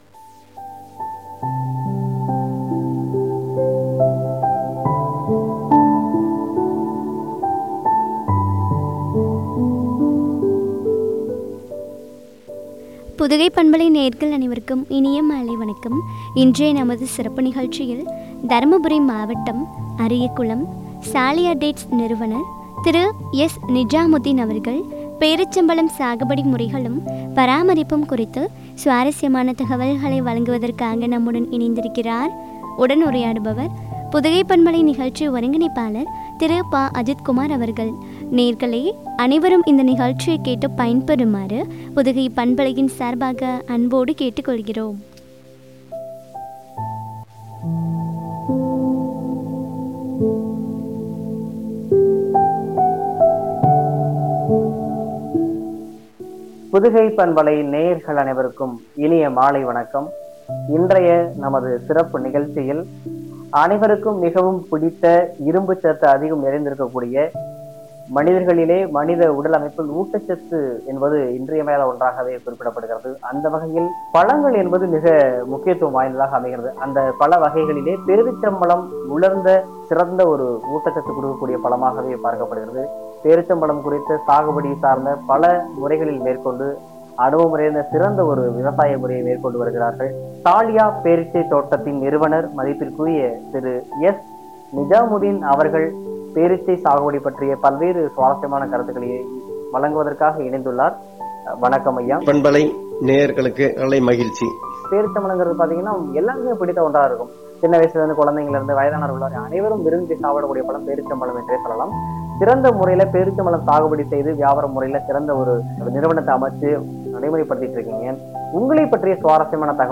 பேரீச்சம்பழம் சாகுபடி – முறைகளும், பராமரிப்பும் குறித்த வழங்கிய உரையாடல்.